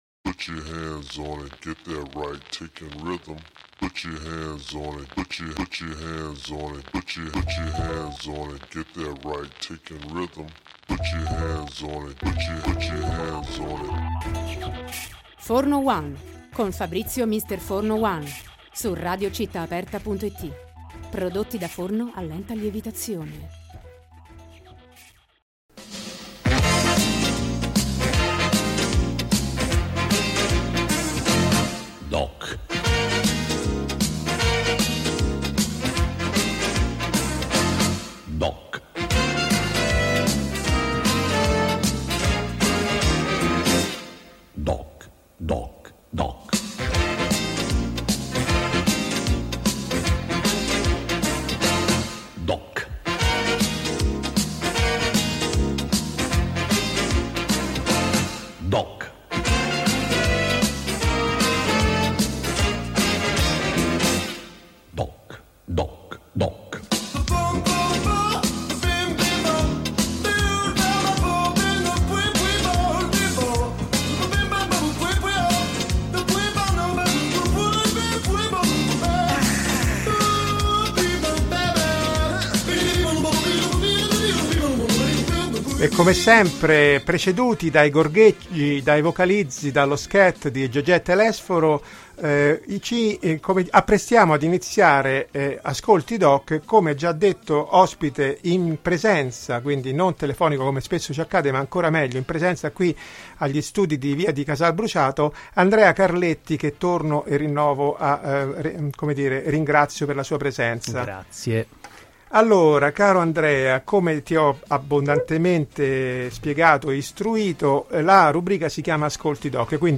Intervista Lemurian per Ascolti DOC